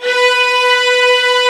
FLSTRINGS1B4.wav